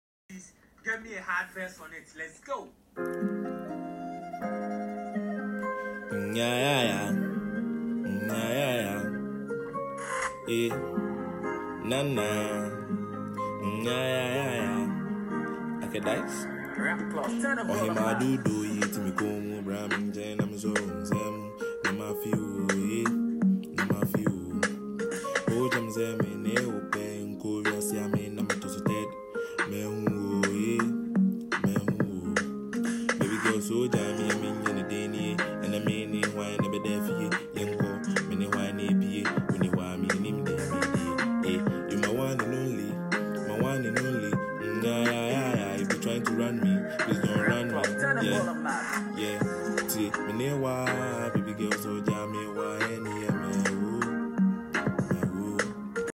Asakaa type beat